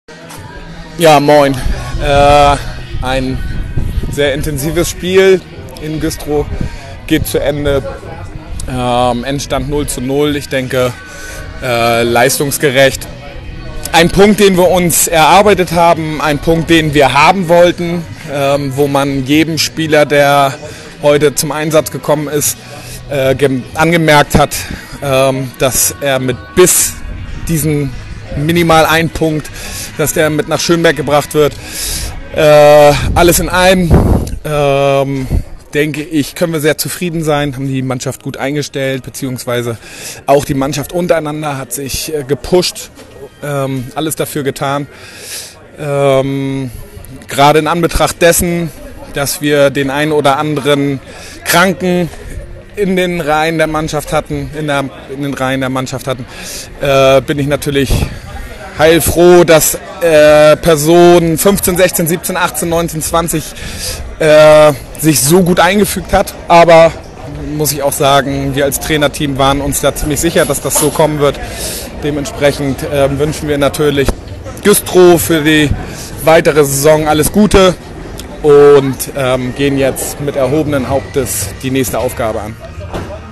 Stimmen